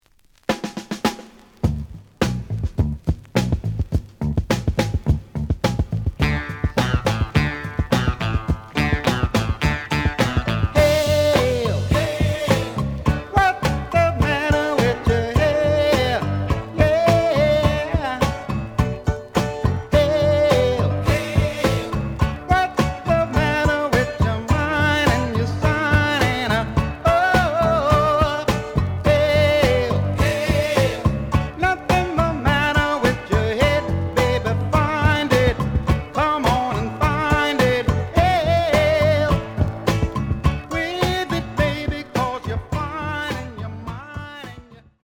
試聴は実際のレコードから録音しています。
The audio sample is recorded from the actual item.
●Genre: Rock / Pop